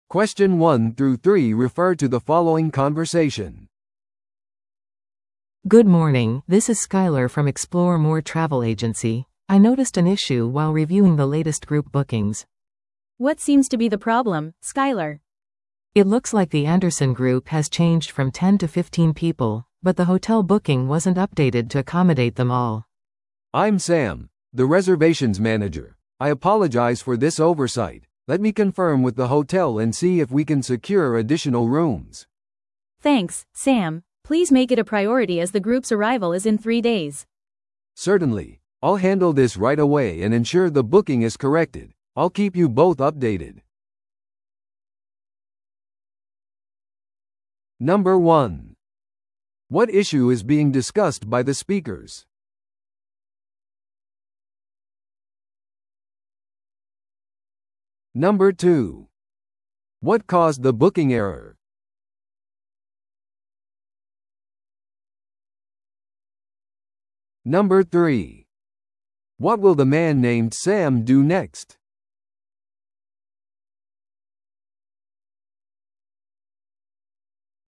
TOEICⓇ対策 Part 3｜団体予約のエラー訂正 – 音声付き No.326